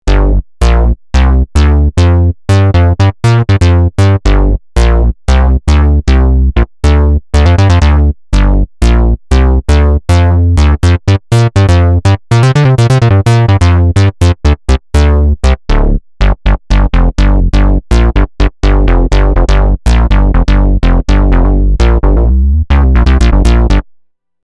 Big Squirt Rez Bass
このしっかりとした存在感のあるベースは特にお気に入りです。